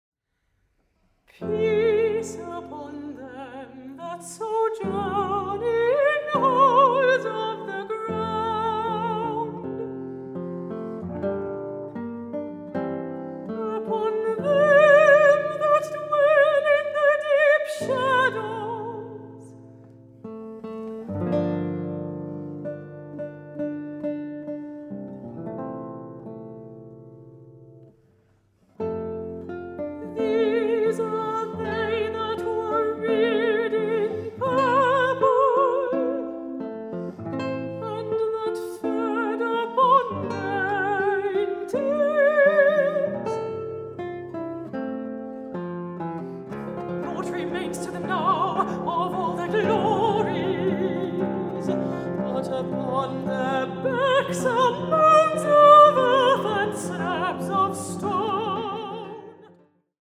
Gitarre
Gesang